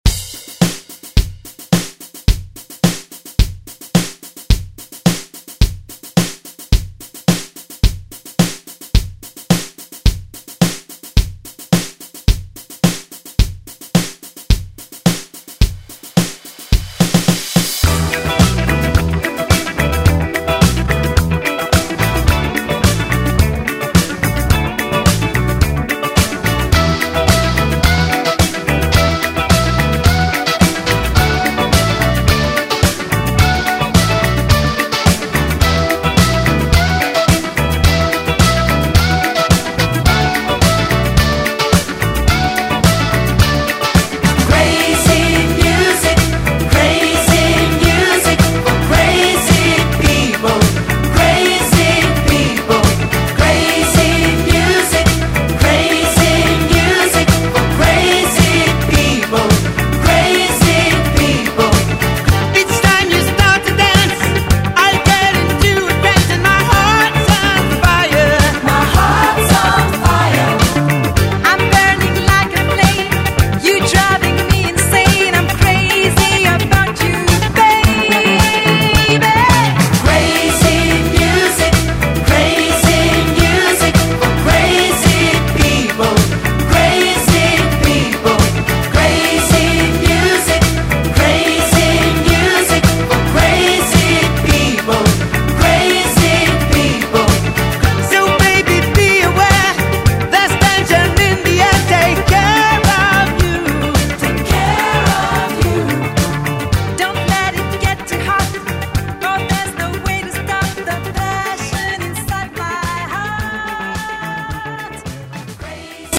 Electronic Pop Rock
119 bpm
Genres: 80's , RE-DRUM , ROCK